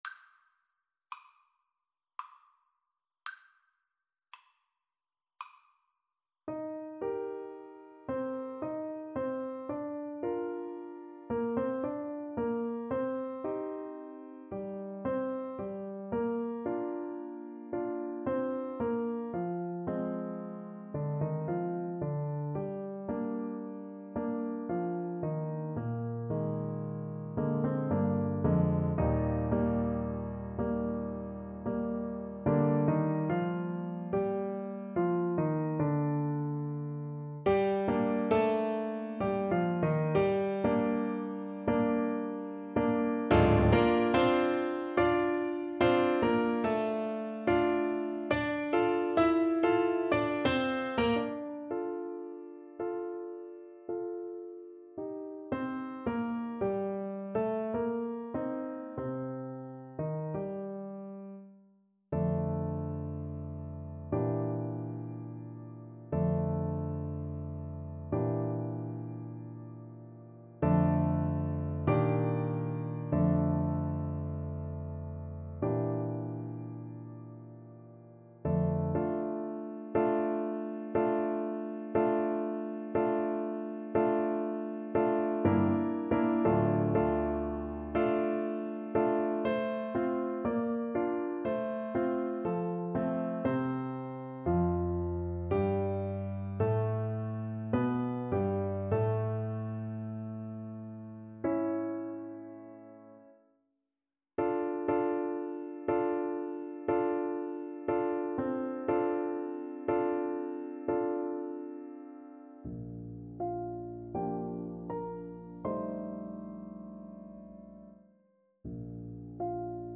Andante molto calmo = 56